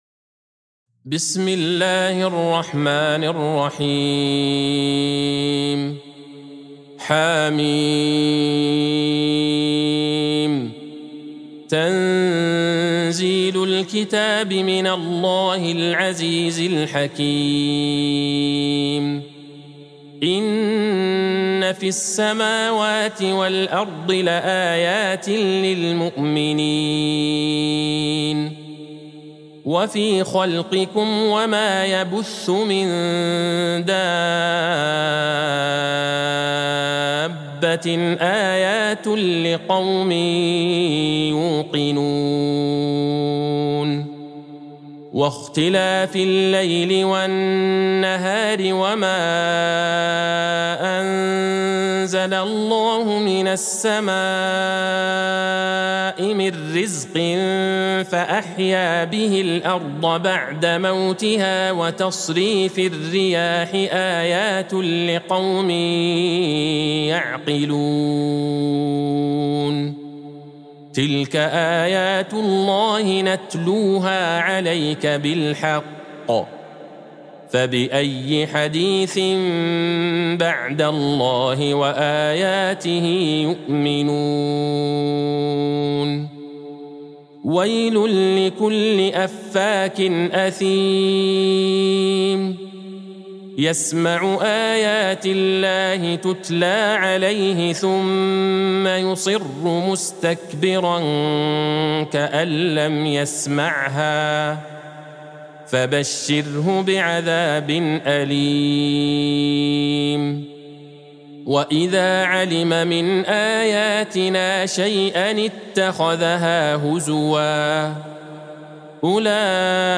سورة الجاثية Surat Al-Jathiyah | مصحف المقارئ القرآنية > الختمة المرتلة